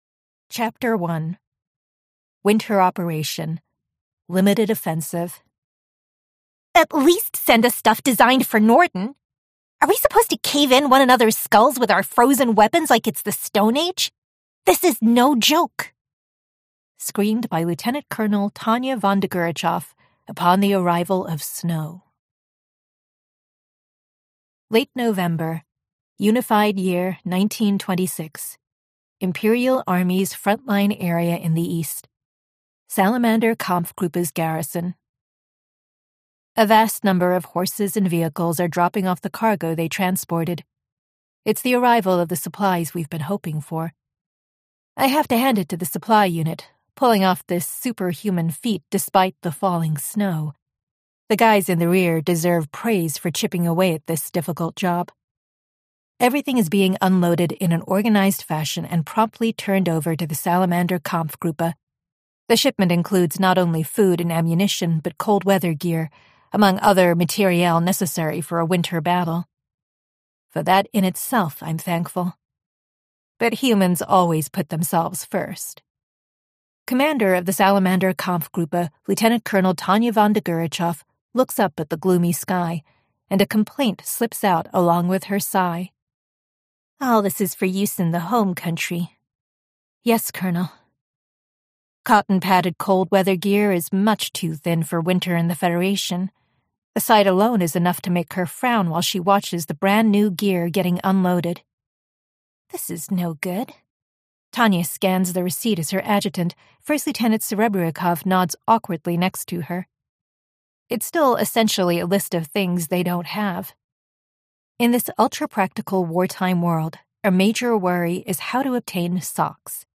Book 6 Unabridged Audiobook Categories